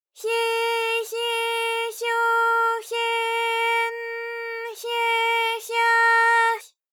ALYS-DB-001-JPN - First Japanese UTAU vocal library of ALYS.
hye_hye_hyo_hye_n_hye_hya_hy.wav